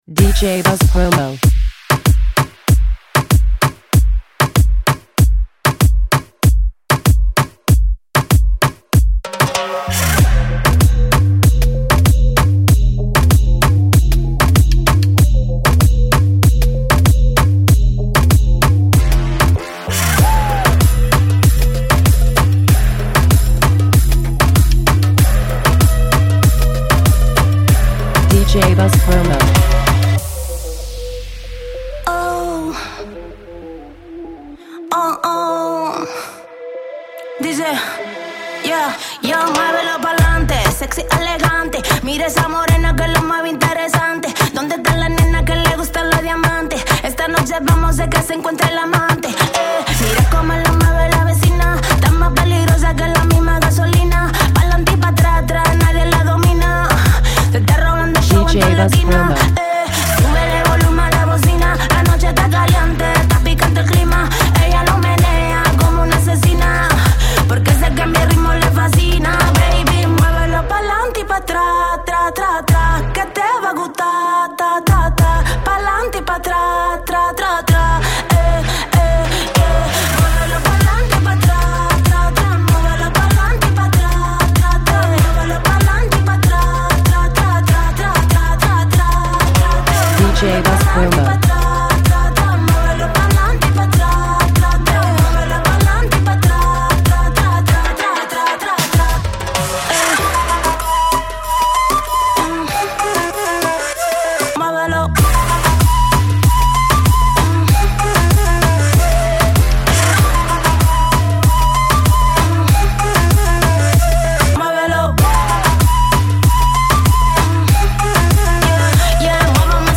is a pure Electro/Reggaeton gem